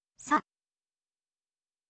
ออกเสียง: sa, สะ